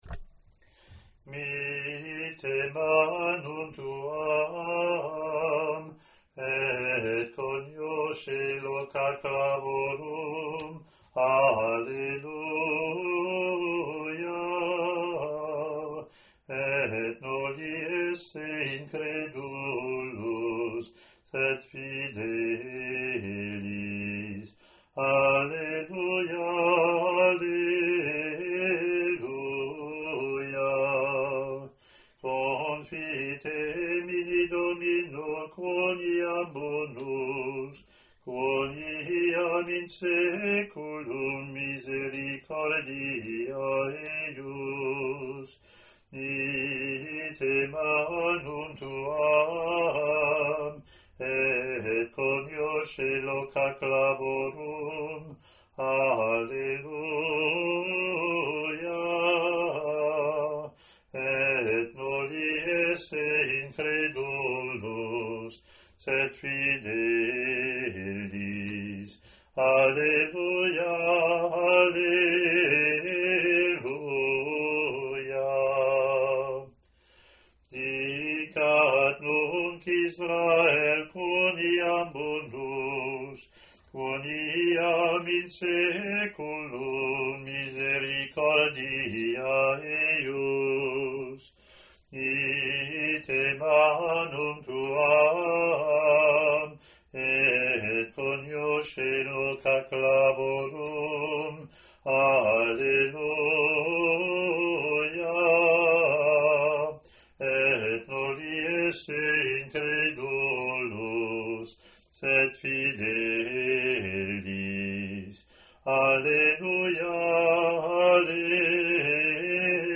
The Roman Missal gives us these Bible verses to be sung per the examples recorded: the congregation joins the cantor for the antiphon (printed), then the cantor sing the Psalm alone, then the congregation and cantor repeat the antiphon.
Latin antiphon + verses)